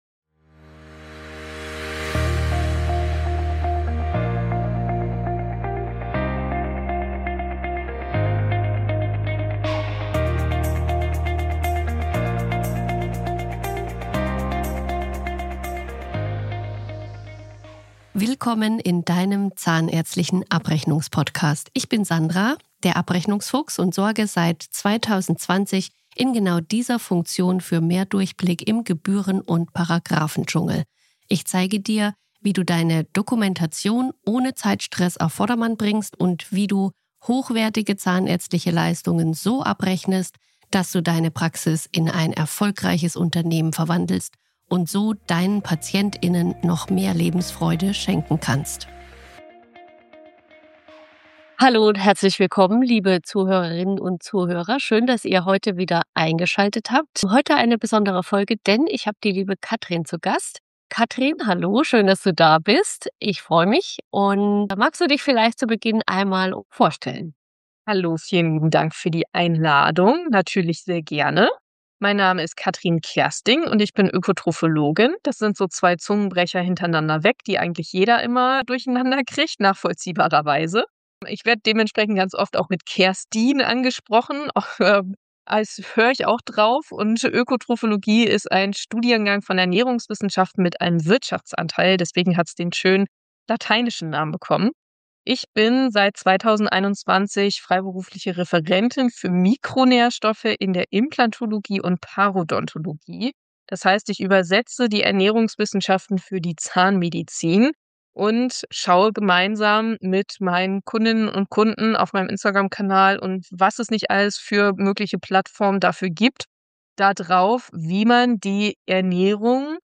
In dieser Gastfolge spreche ich mit Ökotrophologin